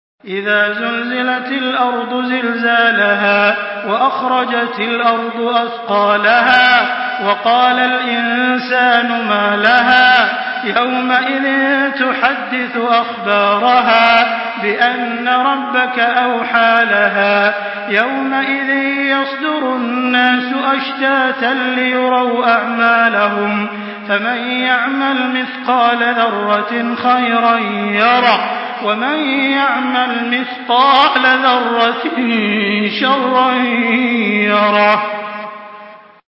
Surah Az-Zalzalah MP3 by Makkah Taraweeh 1424 in Hafs An Asim narration.
Murattal